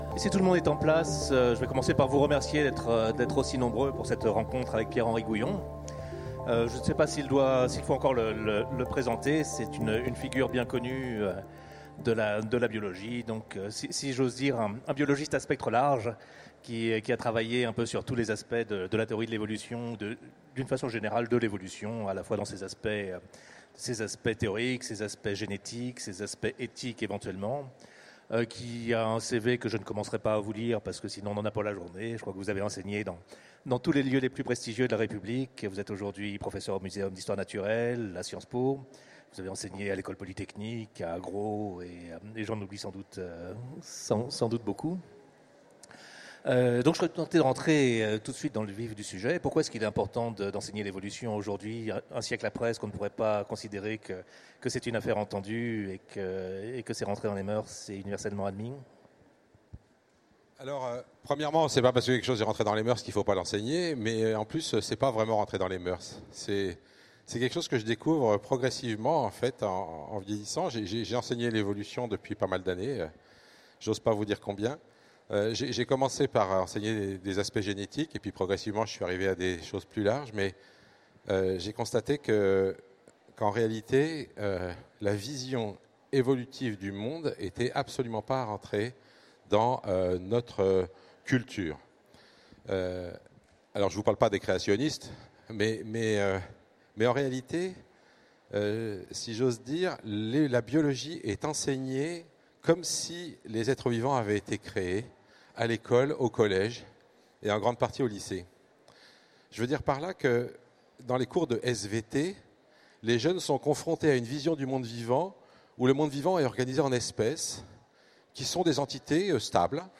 Utopiales 2016 : Rencontre avec Pierre-Henri Gouyon
- le 31/10/2017 Partager Commenter Utopiales 2016 : Rencontre avec Pierre-Henri Gouyon Télécharger le MP3 à lire aussi Pierre-Henri Gouyon Genres / Mots-clés Rencontre avec un auteur Conférence Partager cet article